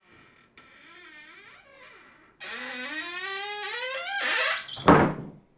Cerrar la puerta de un armario
bisagra
chirriar
chirrido
golpe
rechinar
Sonidos: Acciones humanas
Sonidos: Hogar